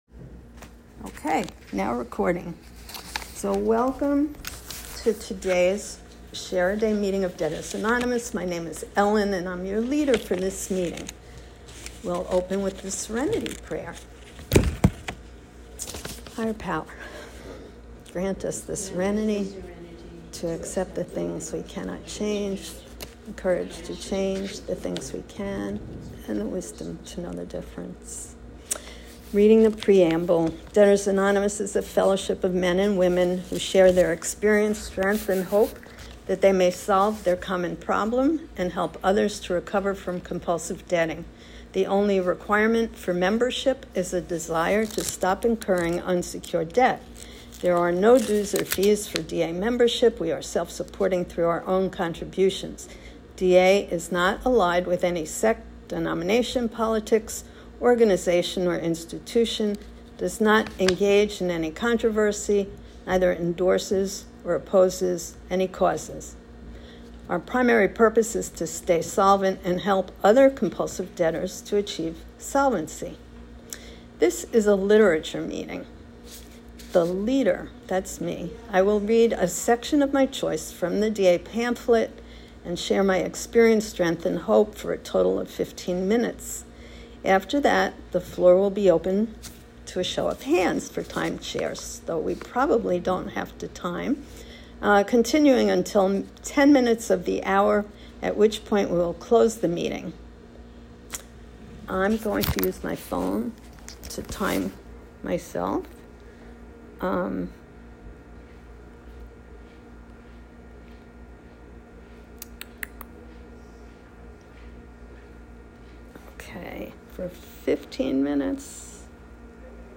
Listen to Debtors Anonymous speakers tell their recovery stories at Share-A-Day. Learn what it was like, how the speakers got to D.A. and what it's like now.